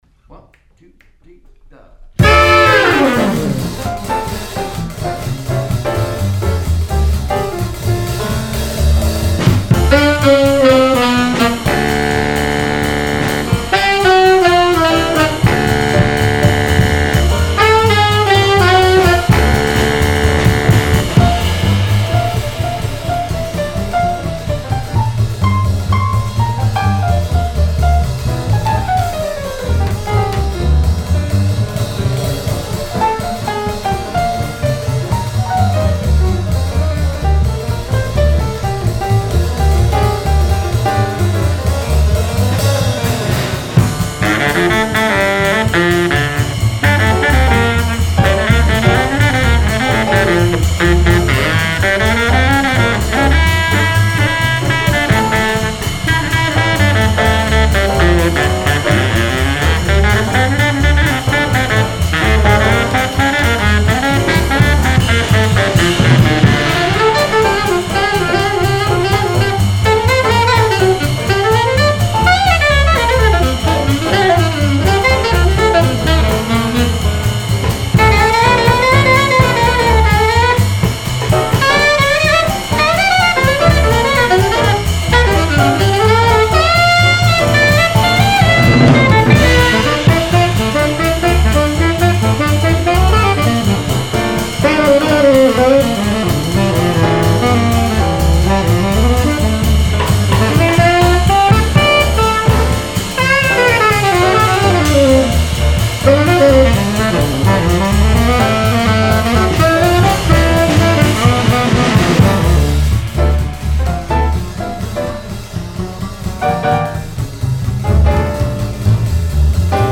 live in the studio